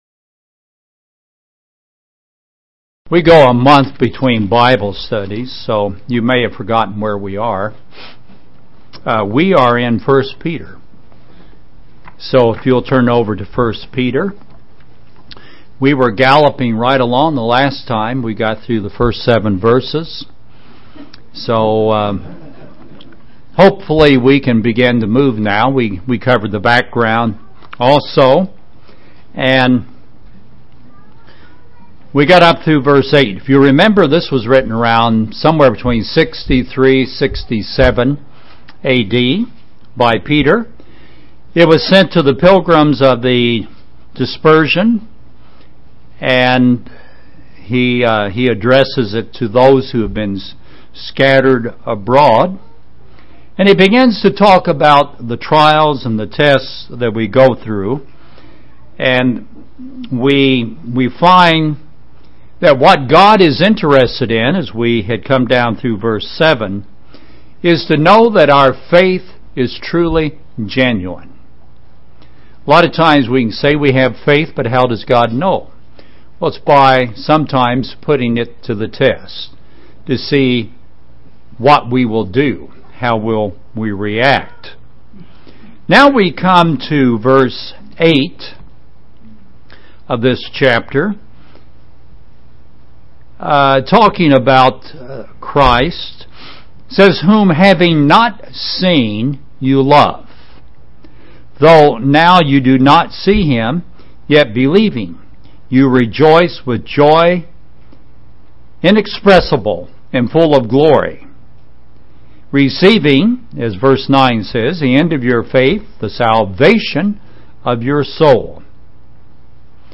A continuing study of 1 Peter 1:8-25.